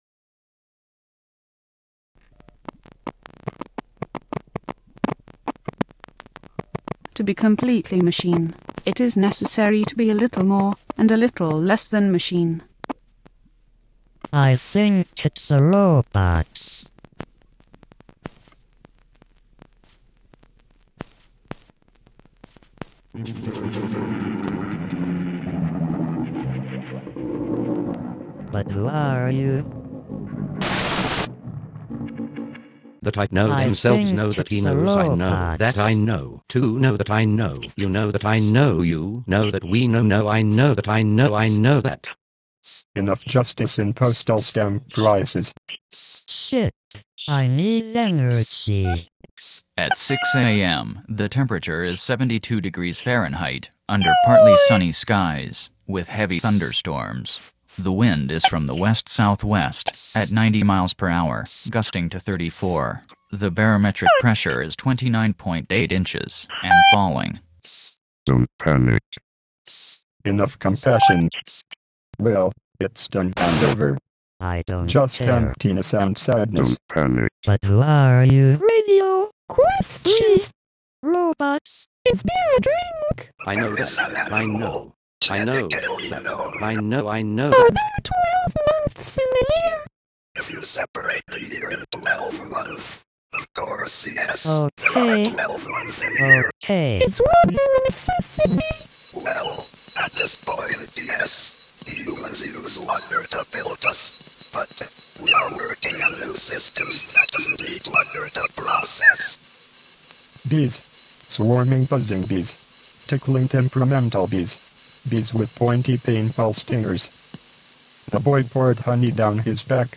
Exhibition & Concert